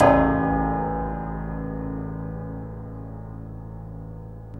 SI2 PIANO03R.wav